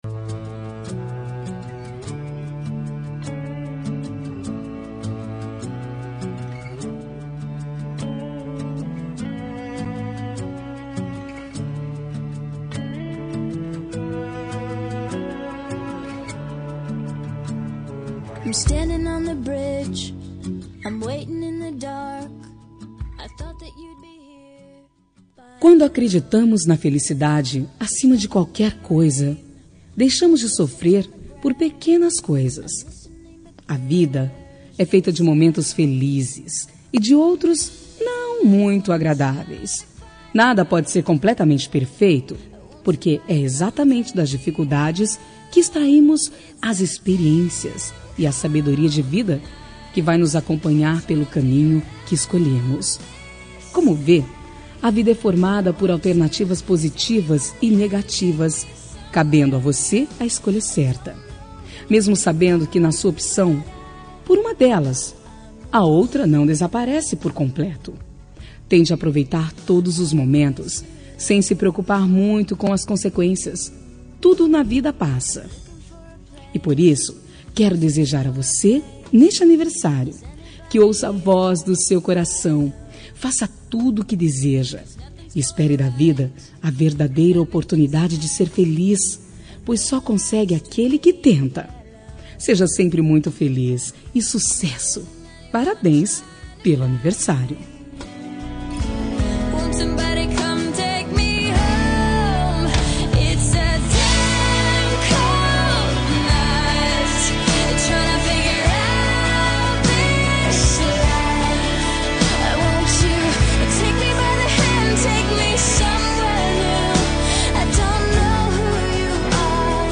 Telemensagem de Aniversário de Pessoa Especial – Voz Feminina – Cód: 1897